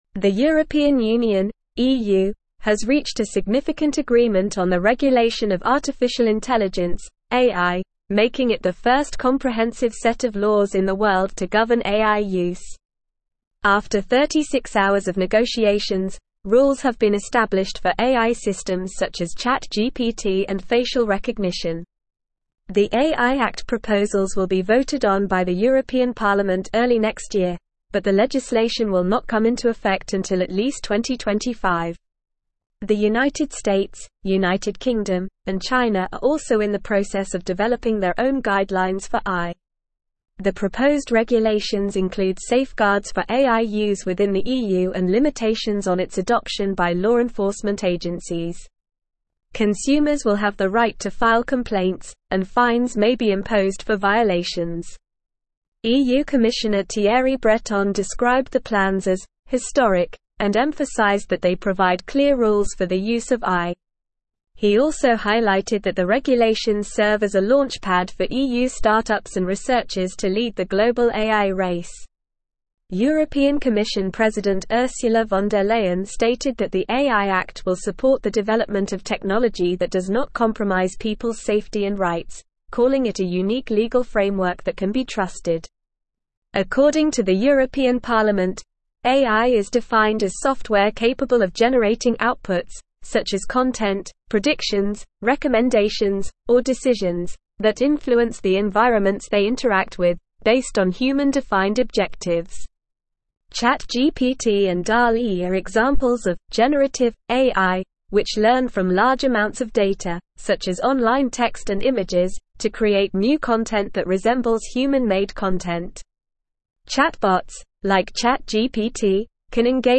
English-Newsroom-Advanced-NORMAL-Reading-EU-Reaches-Historic-Agreement-on-Comprehensive-AI-Regulations.mp3